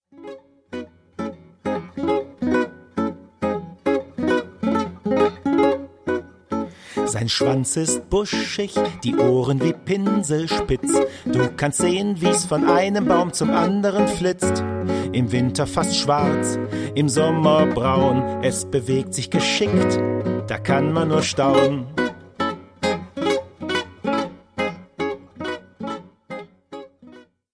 Gesungene Tier- und Pflanzenrätsel